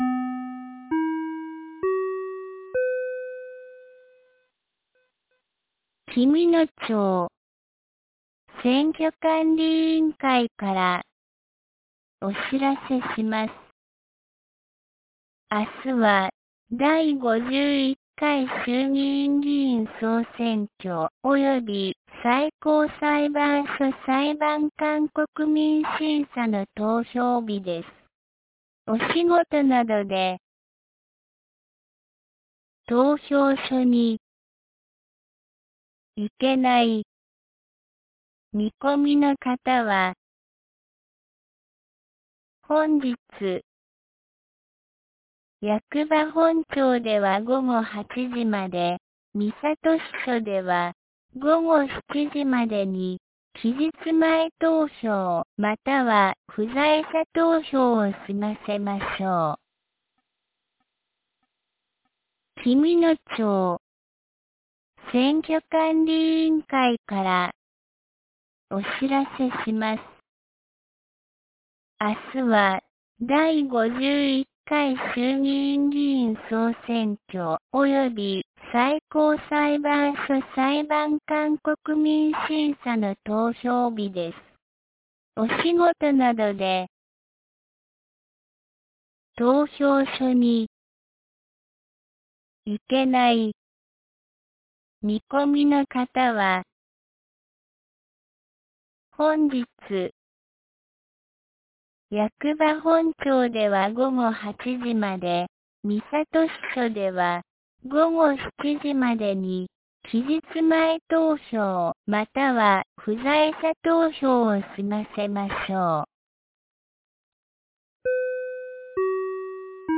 2026年02月07日 09時02分に、紀美野町より全地区へ放送がありました。